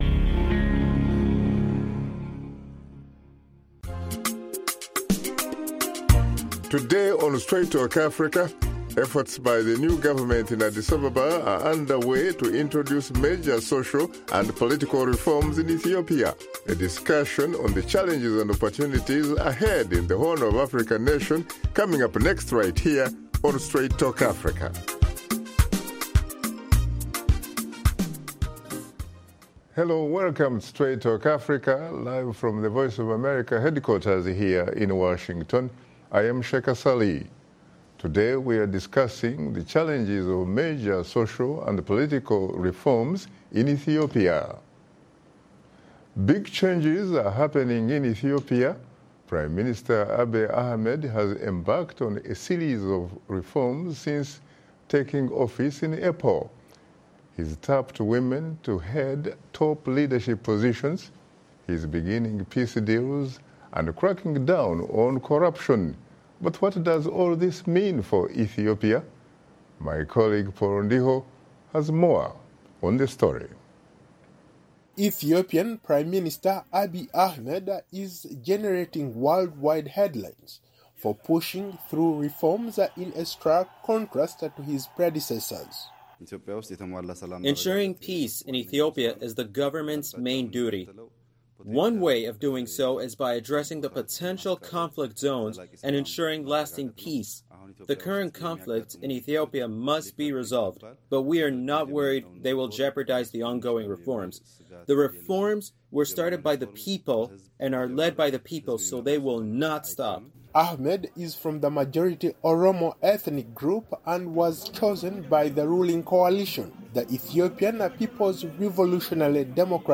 In this episode of Straight Talk Africa veteran journalist Shaka Ssali explores the challenges of major social and political reforms in Ethiopia that the new Prime Minister Abiy Ahmed is undertaking.